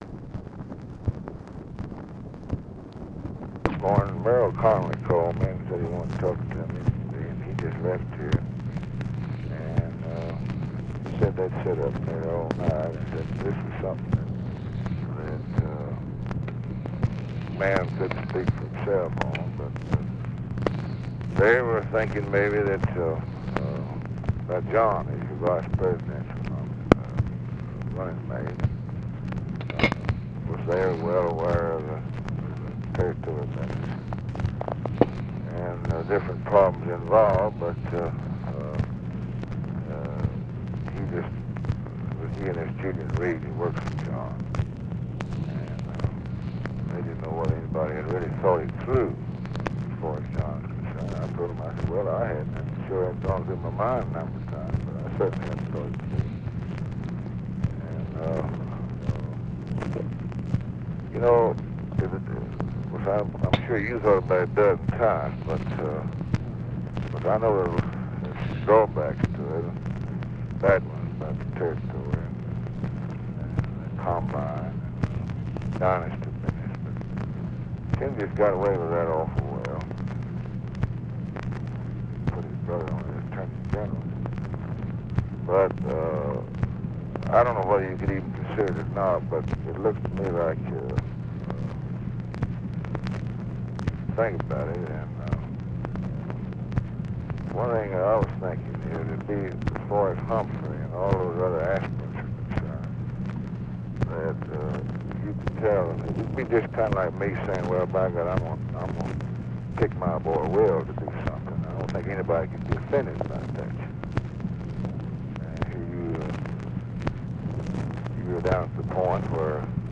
Conversation with A. W. MOURSUND, August 25, 1964
Secret White House Tapes